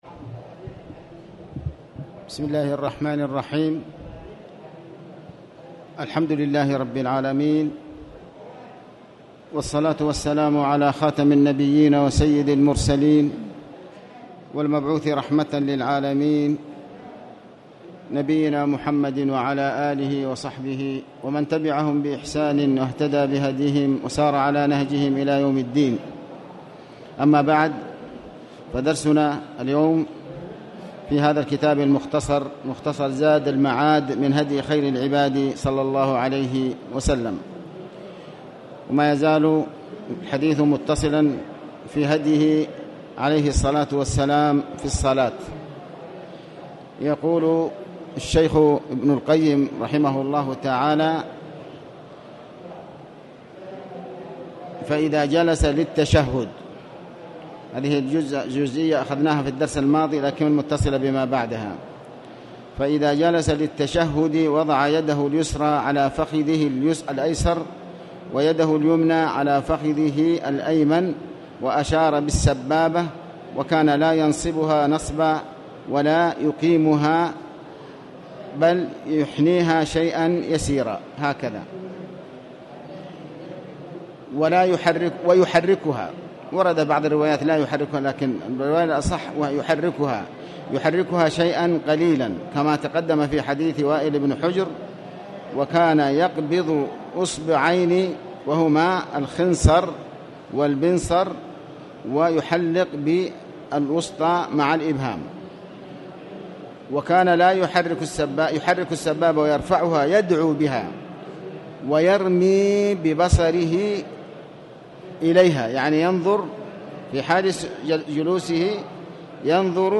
تاريخ النشر ٢٩ صفر ١٤٤٠ هـ المكان: المسجد الحرام الشيخ: علي بن عباس الحكمي علي بن عباس الحكمي صفة الصلاة